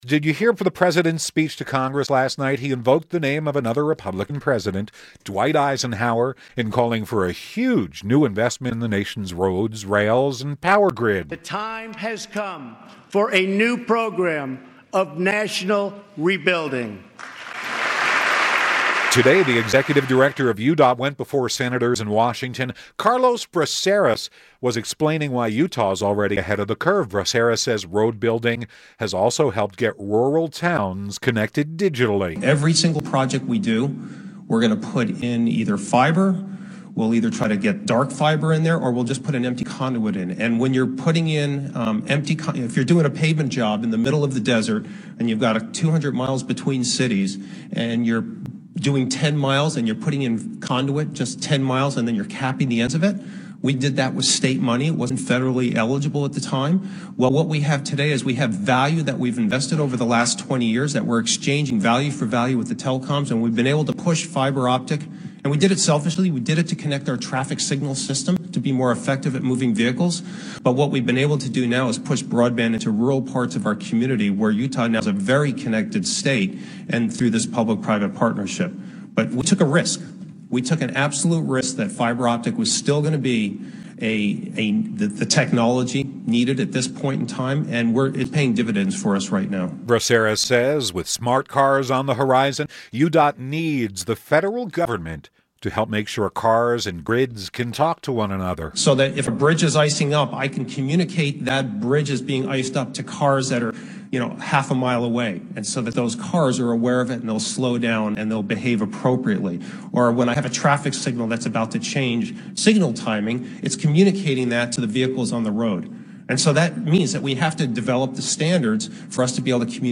Utah Department of Transportation Executive Director Carlos Braceras addressed the Senate Commerce, Science and Transportation Committee in Washington, D.C. on Wednesday. He described Utah's investment in fiber optics to connect roads and rural communities. He also urged lawmakers to streamline regulations and funding for road building.